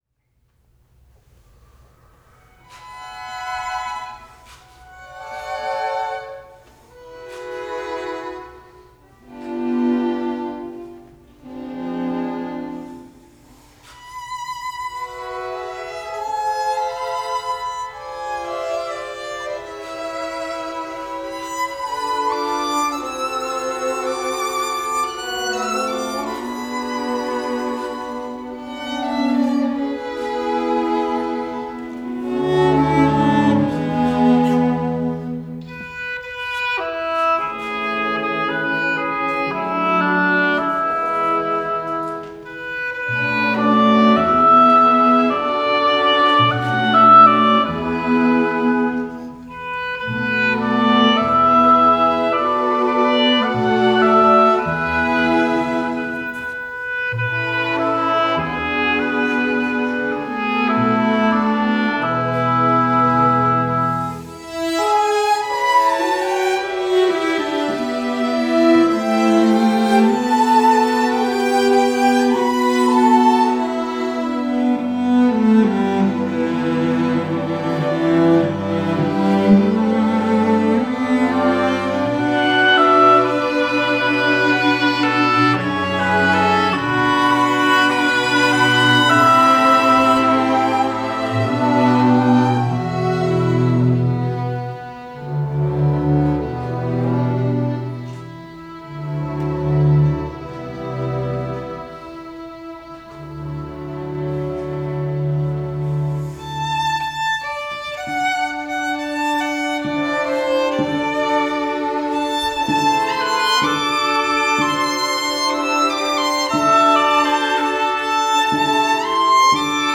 Oboe
Barlow's "Winter's Passed" with the Montgomery College Orchestra: